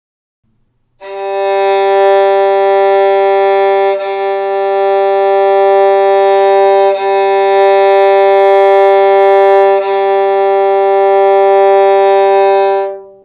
• G3, the thickest string
Tuning-the-violin-for-Beginners-sound-of-open-G-string.mp3